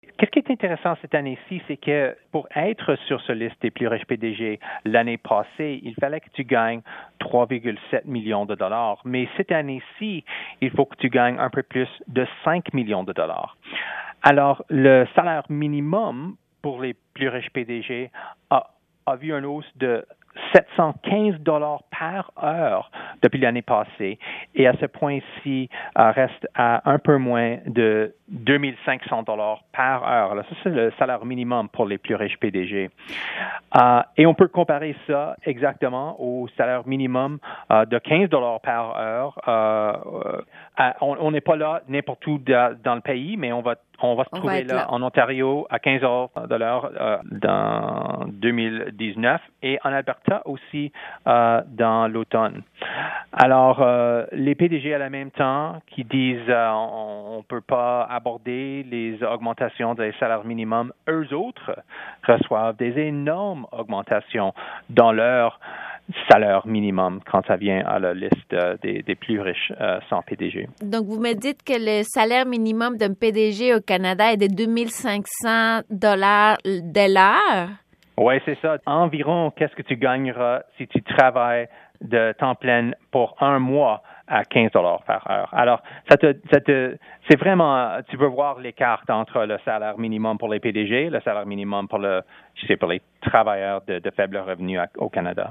En entrevue avec Radio Canada International